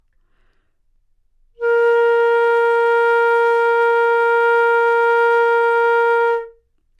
长笛单音 " 单音的整体质量 长笛 A4
Tag: 纽曼-U87 单注 多样本 Asharp4 好声音 长笛